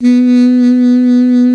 55af-sax04-B2.wav